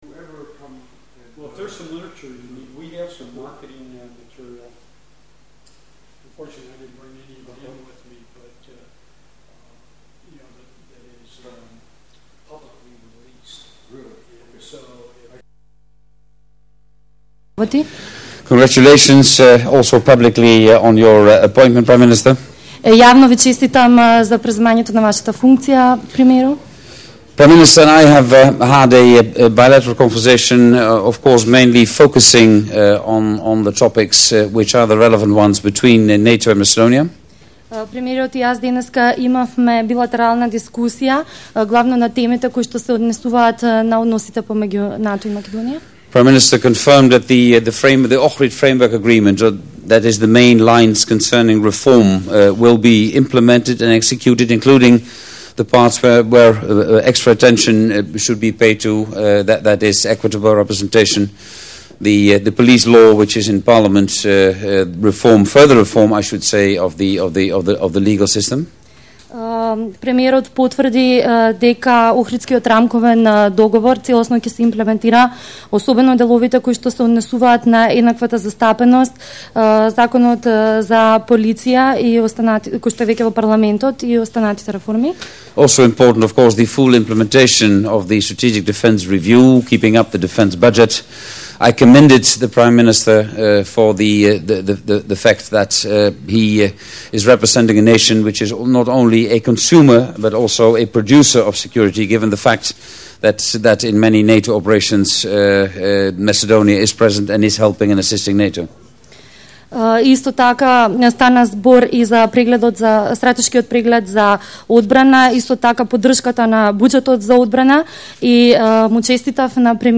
Audio Press point with NATO Secretary General, Jaap de Hoop Scheffer and the Prime-Minister of the former Yugoslav Republic of Macedonia¹, Mr. Nikola Gruevski, opens new window